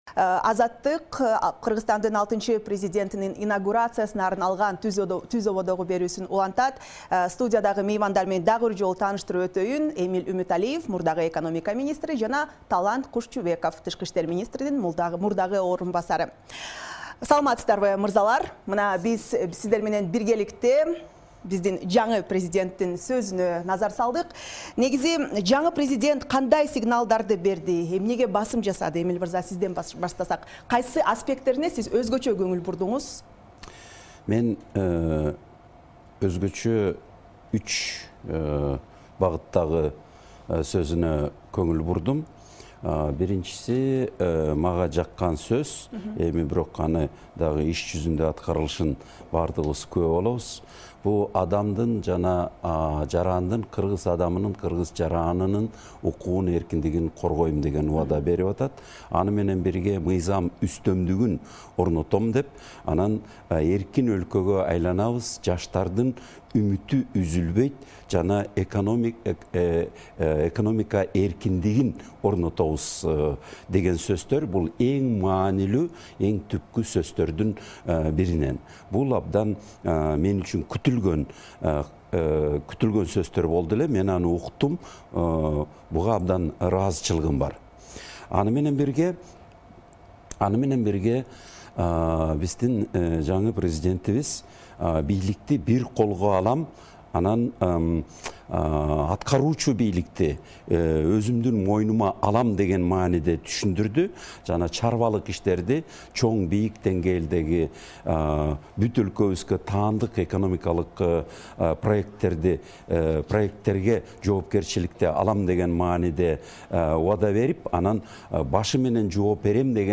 Токтогул Сатылганов атындагы улуттук филармонияда ант берүү иш-чарасында сүйлөгөн сөзүндө президент Садыр Жапаров Орусияны, Казакстанды, Өзбекстанды тышкы саясаттагы артыкчылыктуу багыт экенин белгиледи. Жапаровдун ушул жана башка билдирүүлөрүнө "Азаттыктын" студиясында эксперттер өз баасын берди.